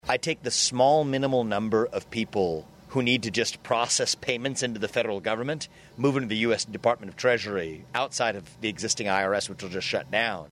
RAMASWAMY MADE HIS COMMENTS DURING A RECENT INTERVIEW WITH RADIO IOWA.